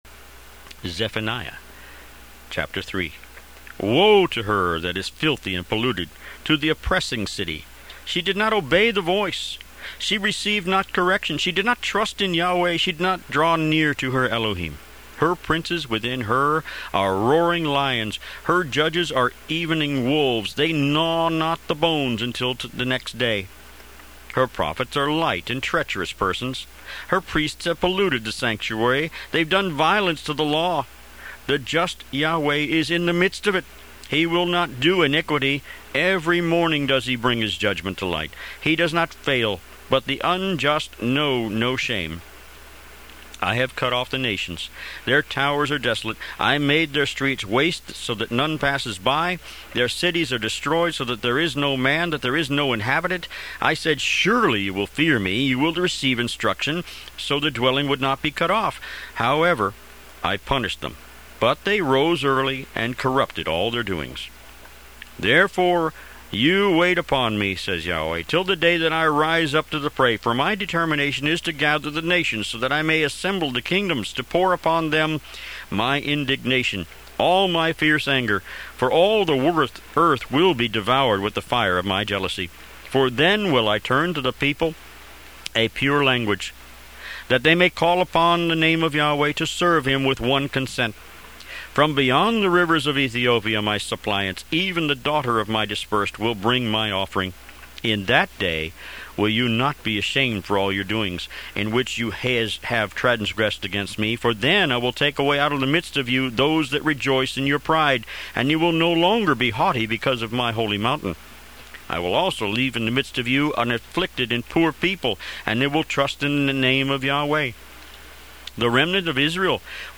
Tanakh - Jewish Bible - Audiobook > 36 Zephaniah